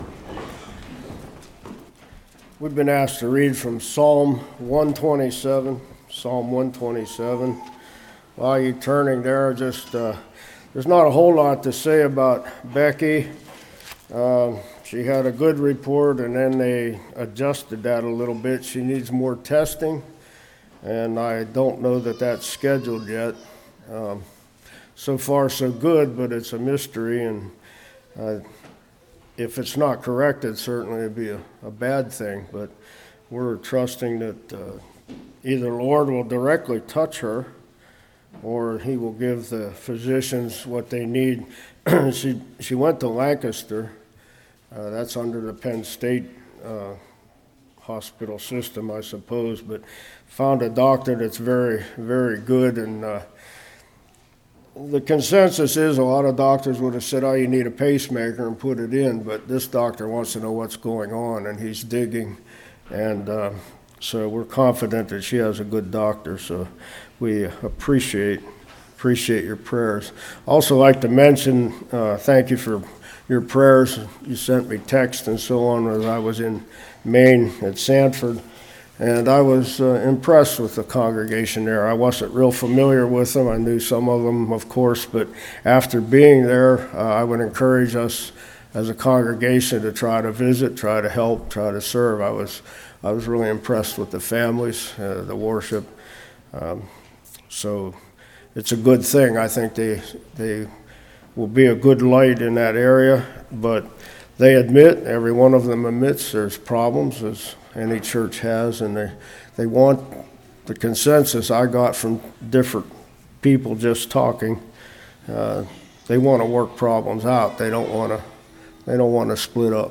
Psalms 127:1-5 Service Type: Morning Children As Arrows Will Your Children Influence The World?